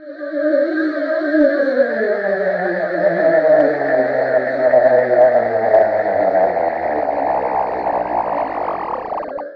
gurgling_theremin_1.mp3